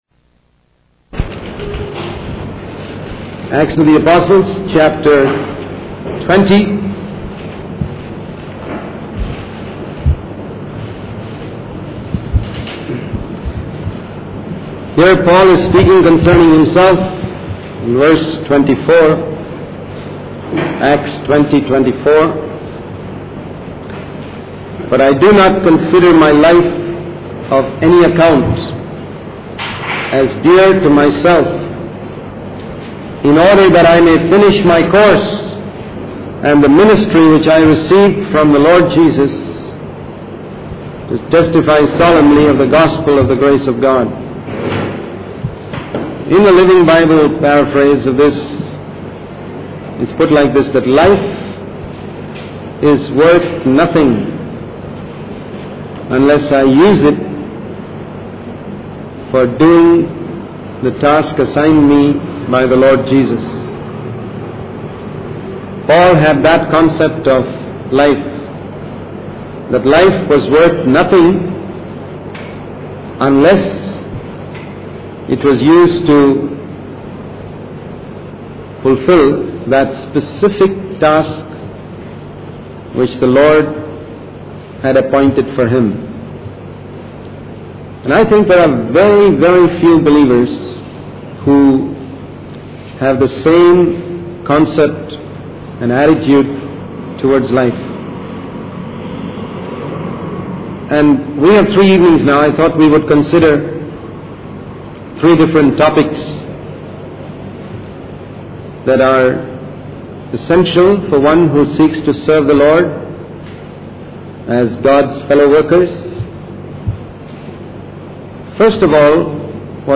In this sermon, the speaker addresses the issue of being zealous and radical in one's faith versus being diplomatic and sitting on the fence.